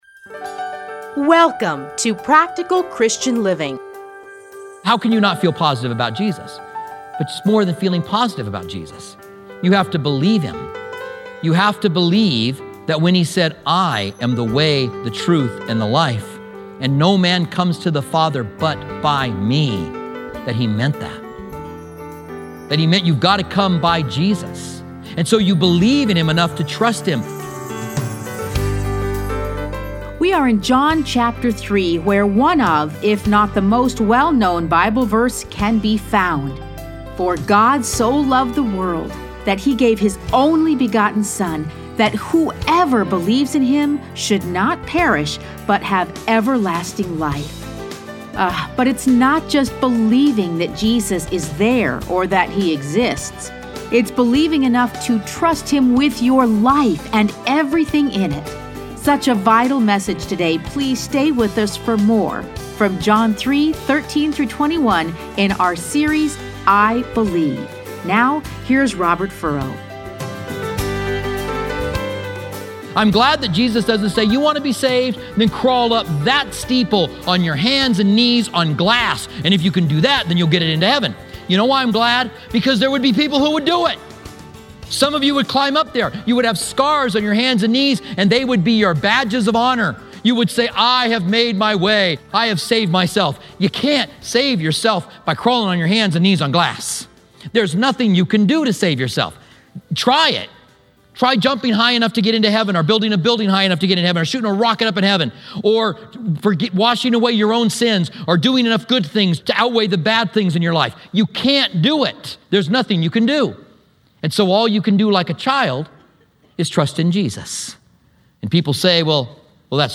Listen to a teaching from John 3:12-21.